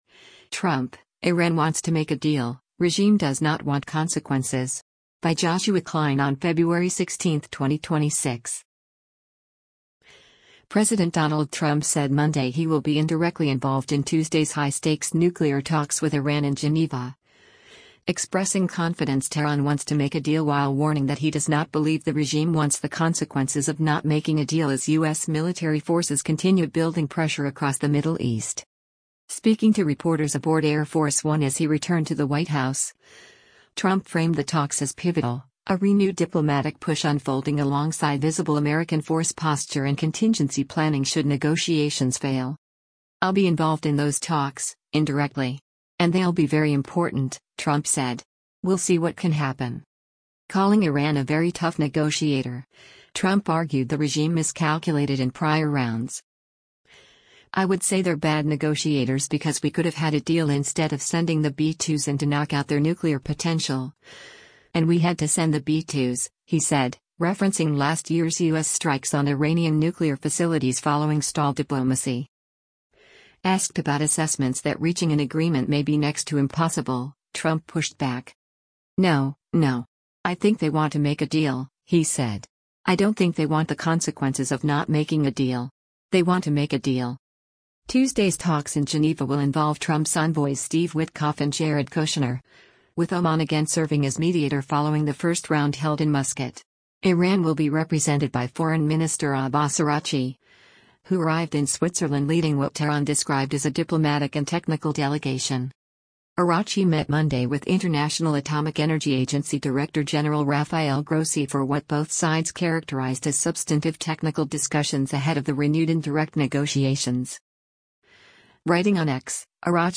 US President Donald Trump speaks to members of the media on the South Lawn of the White Ho